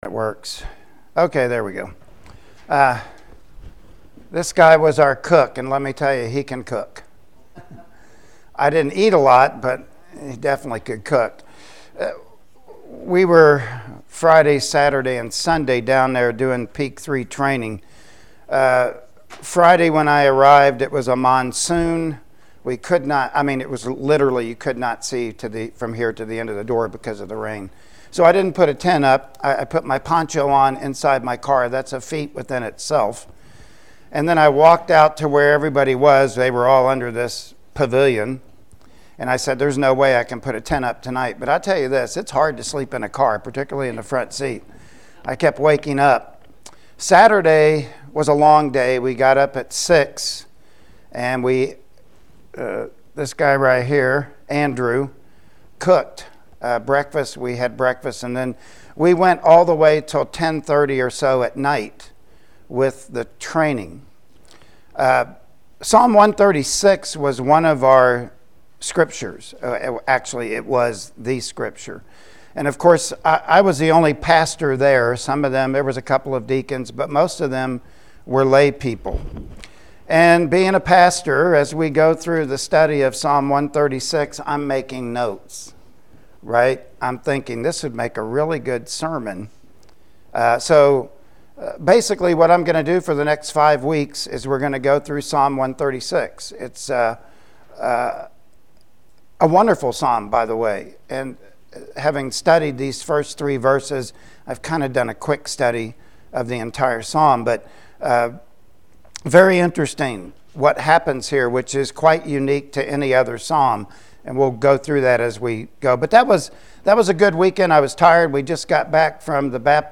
-13 Service Type: Sunday Morning Worship Service Topics: God is Sovereign « Life Under Heaven Overflow